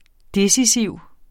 Udtale [ ˈdesiˌsiwˀ ]